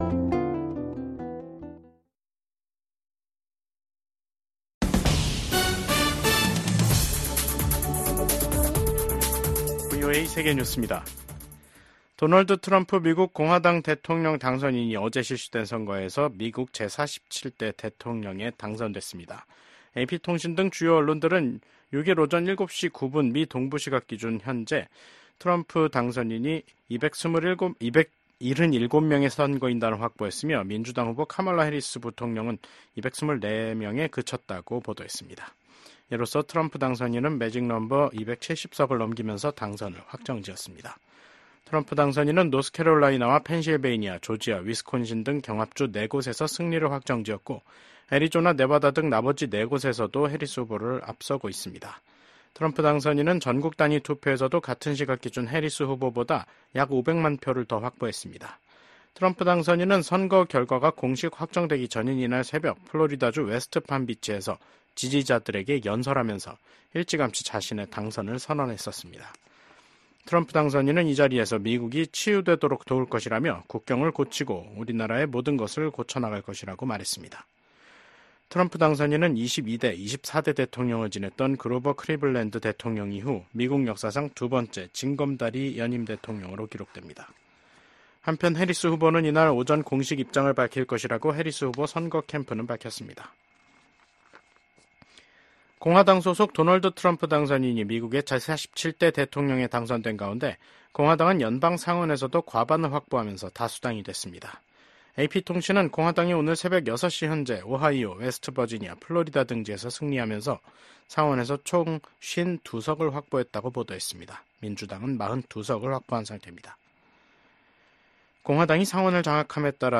세계 뉴스와 함께 미국의 모든 것을 소개하는 '생방송 여기는 워싱턴입니다', 2024년 11월 6일 저녁 방송입니다. 트럼프 후보가 미국의 제47대 대통령에 당선됐습니다. 그는 6일 새벽 플로리다주 팜비치에서 승리를 선언하고 연설했습니다.